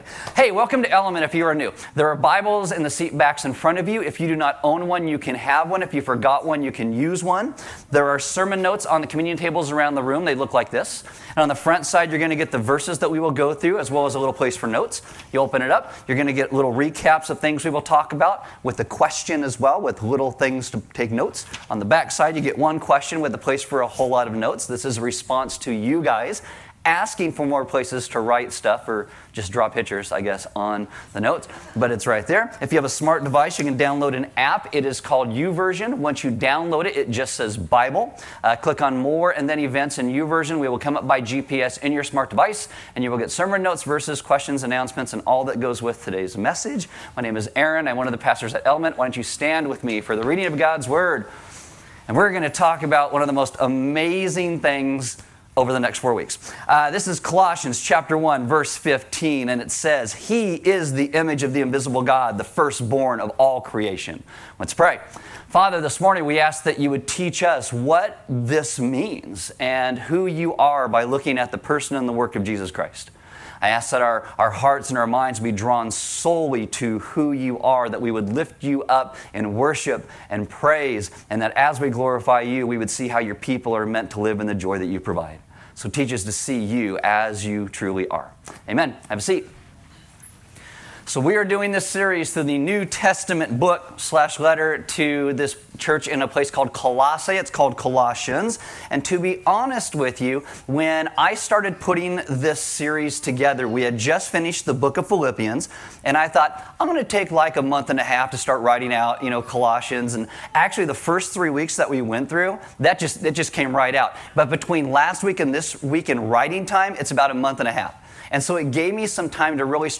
Service Audio Message We are going to take a few weeks to look at Colossians 1:15-20 as it is one of the highest Christological statements anywhere in the Bible.